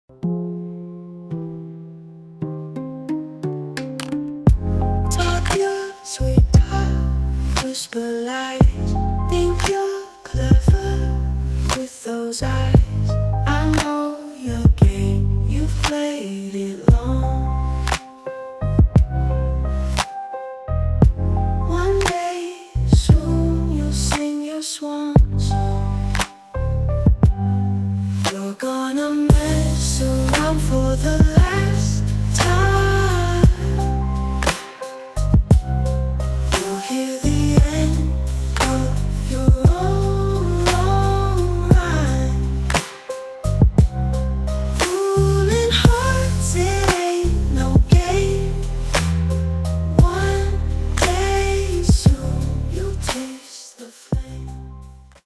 Acoustic
An incredible Hip Hop song, creative and inspiring.